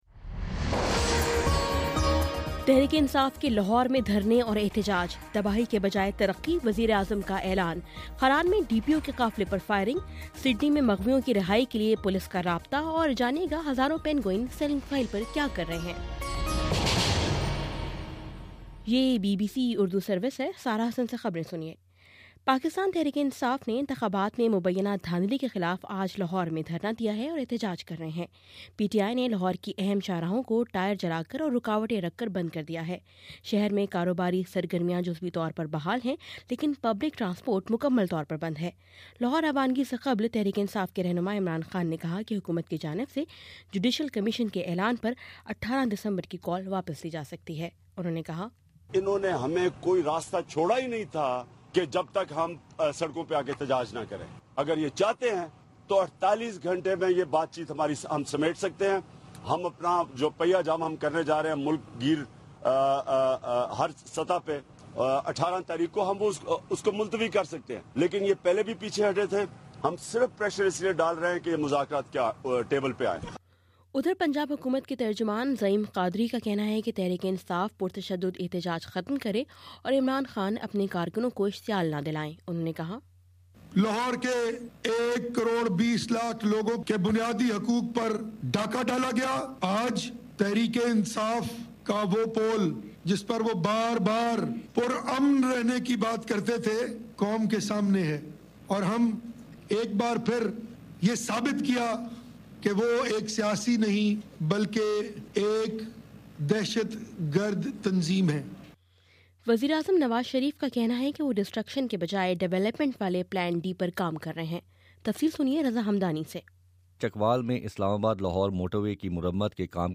دسمبر15: شام سات بجے کا نیوز بُلیٹن